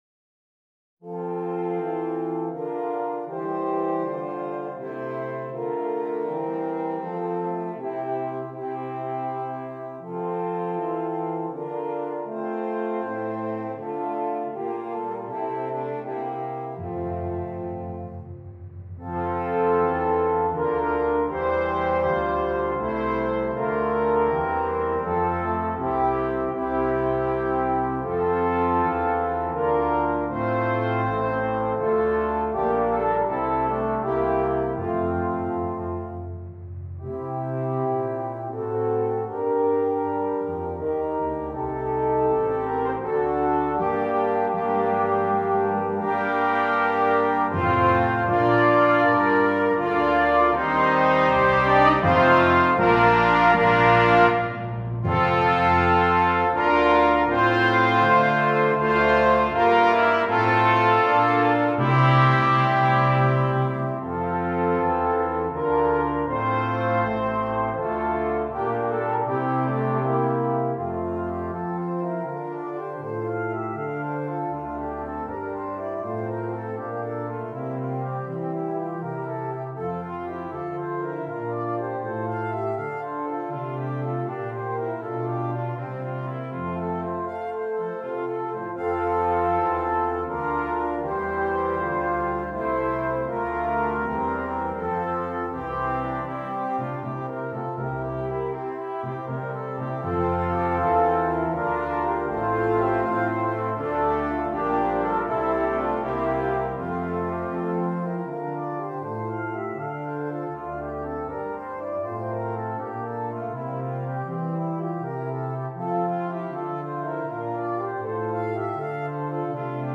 Brass Choir (4.4.3.1.1.perc)